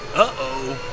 wuh oh sound effects
wuh-oh